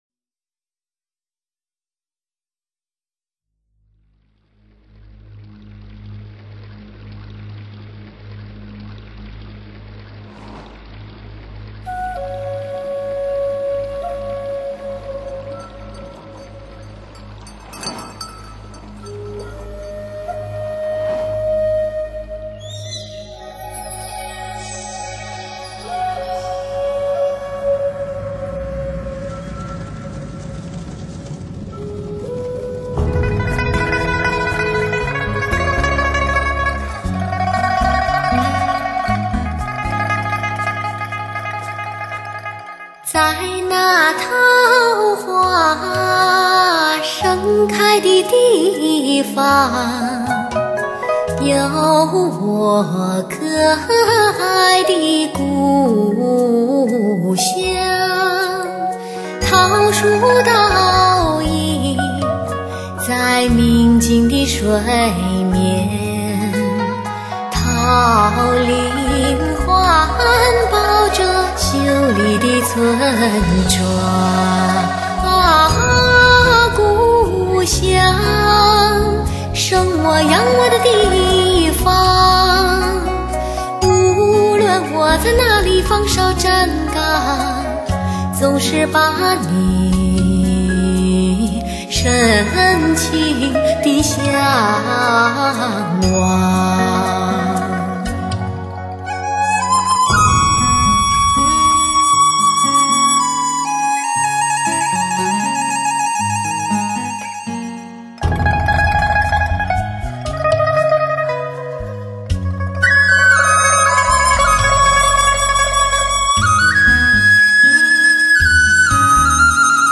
既保留了黑胶LP唱盘的高保真 与自然感，又具备了CD的高清晰与低噪音的优点